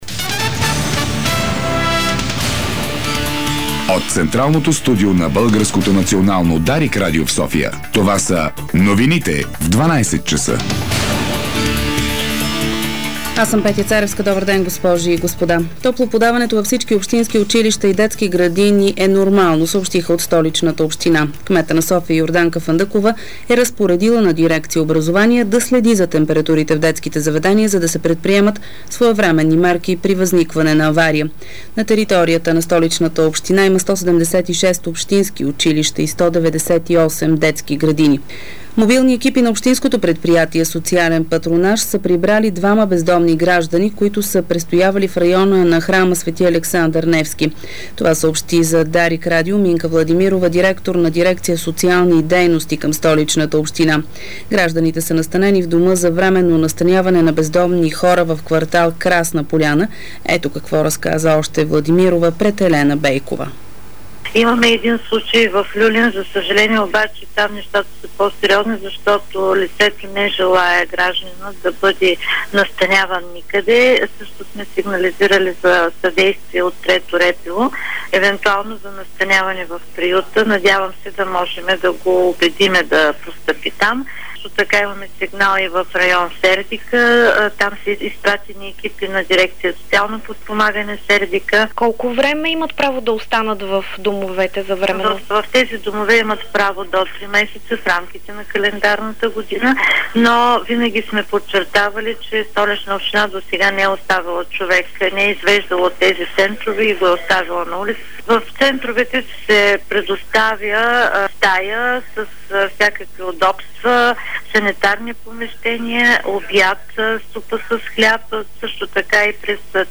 Обедна информационна емисия - 21.12.2009